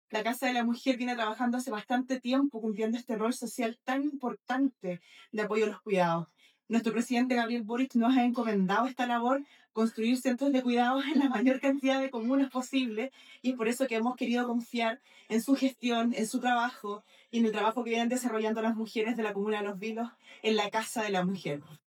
Camila Sabando – Seremi de la Mujer y la Equidad de Genero Coquimbo